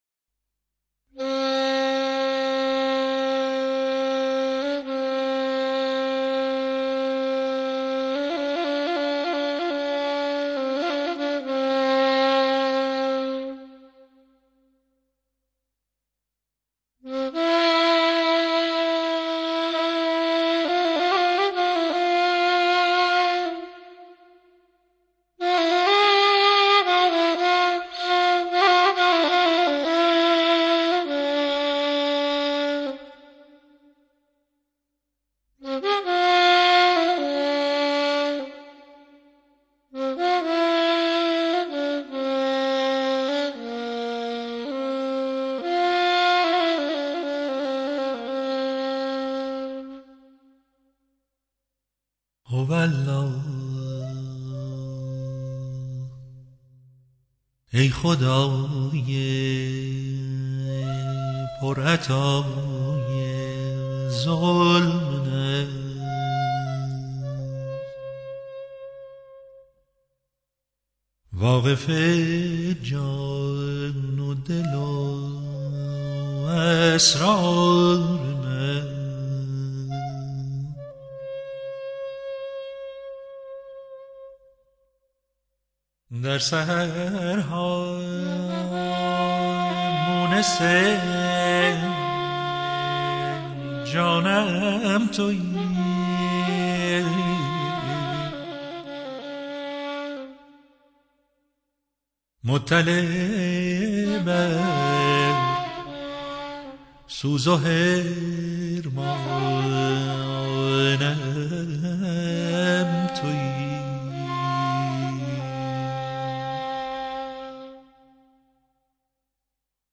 هر دو در یک دستگاه هستند و طبیعیه که حس مشابهی داشته باشید.